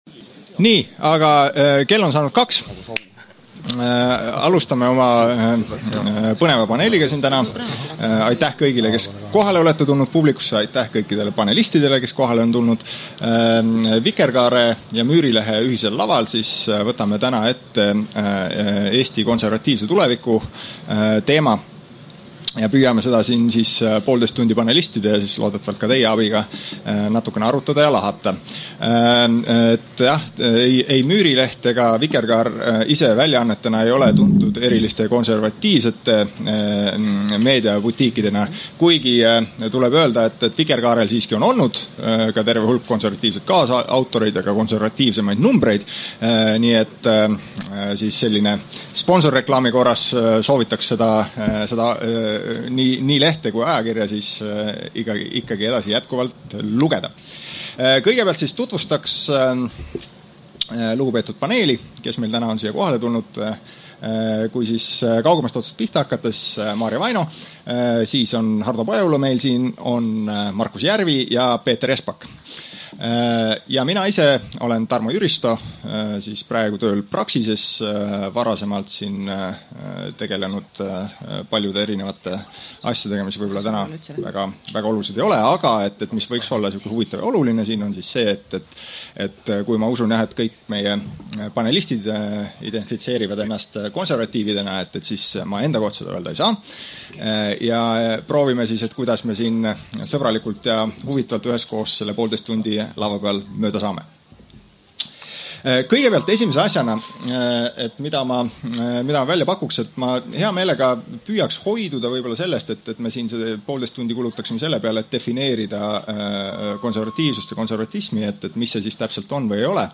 Järgnevatel päevadel postitame oma blogisse salvestusi Vikerkaare ja Müürilehe ühislavalt tänavusel Arvamusfestivalil.